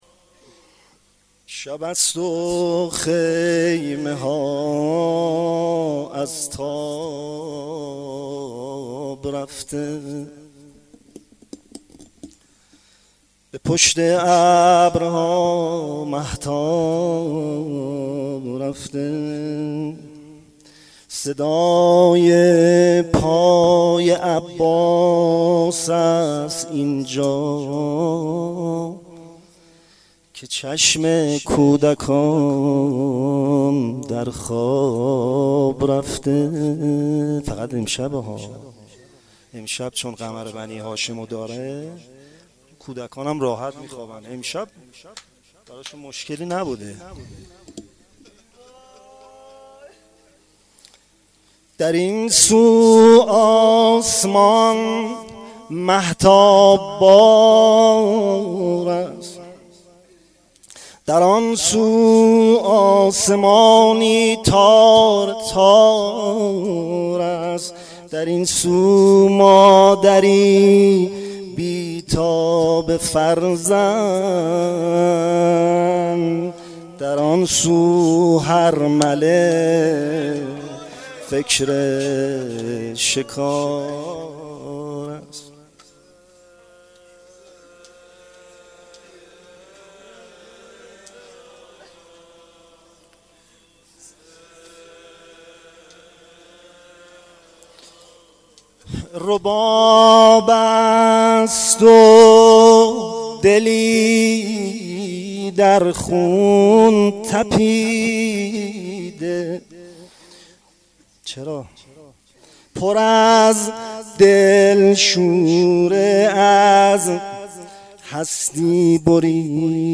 روضه شب عاشورا محرم 93